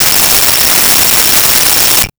Cell Phone Ring 05
Cell Phone Ring 05.wav